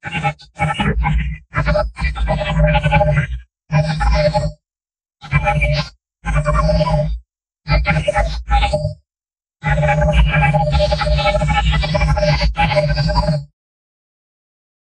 robot-sounds-background-5w3jxzsm.wav